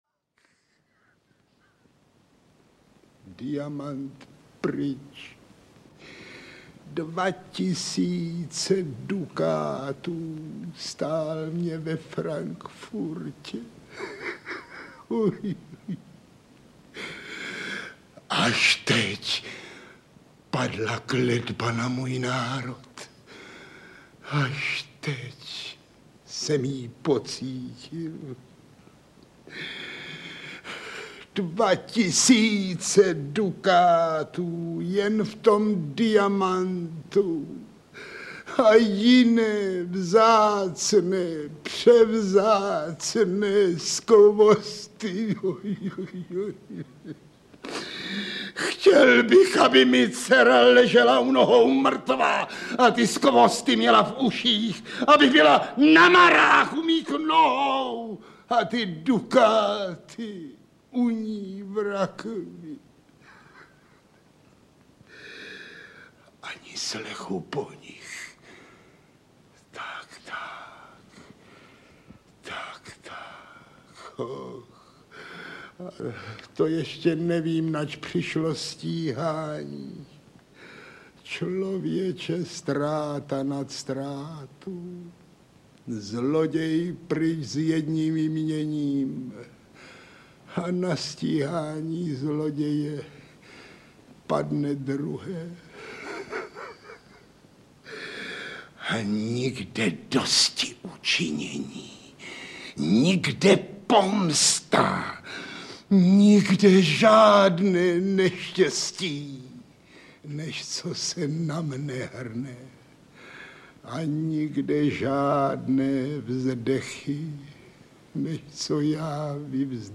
Národní umělec Zdeněk Štěpánek audiokniha
Ukázka z knihy